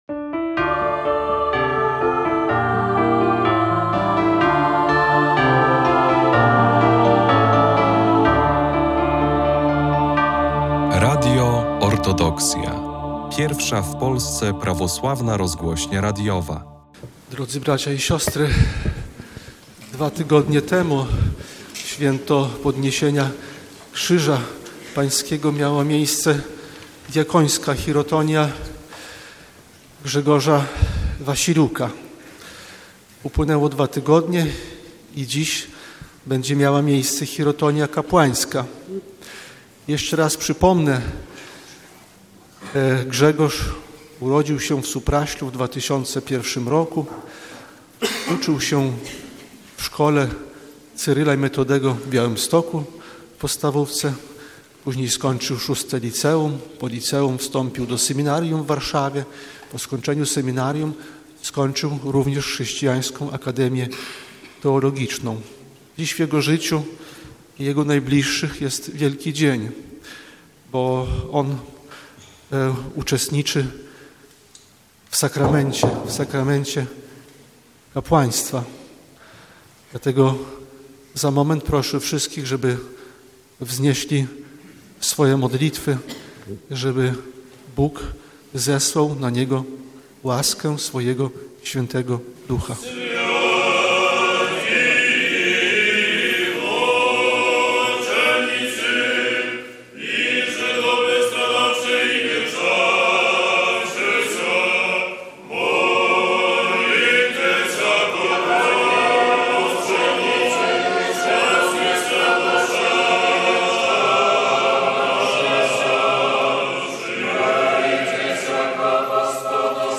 12 października, w XVIII Niedzielę po święcie Pięćdziesiątnicy, JE Najprzewielebniejszy Jakub Arcybiskup Białostocki i Gdański odprawił Boską Liturgię w białostockiej Katedrze św. Mikołaja.